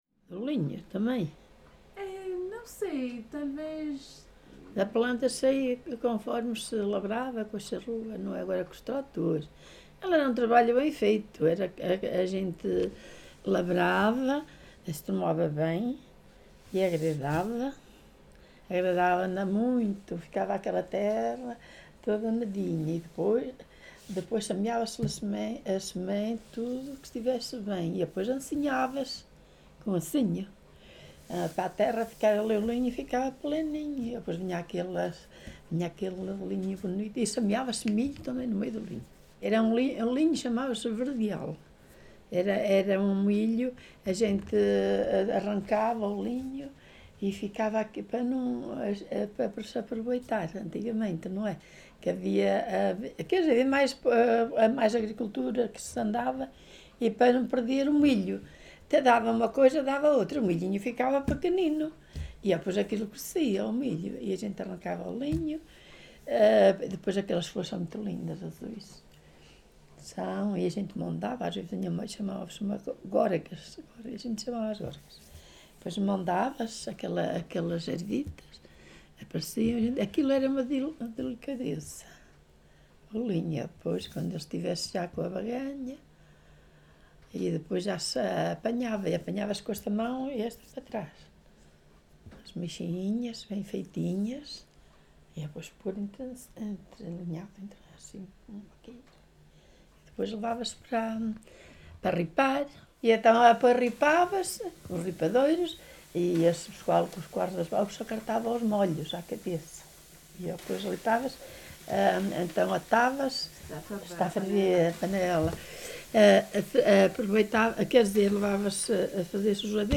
Várzea de Calde, primavera de 2019. Registo sonoro integrado numa série de gravações realizadas para o projeto Viseu Rural 2.0 e para o Arquivo da Memória de Várzea de Calde, cujo tema principal são diversos usos e conhecimentos sobre plantas: medicina, culinária, construção de ferramentas...
Tipo de Prática: Inquérito Oral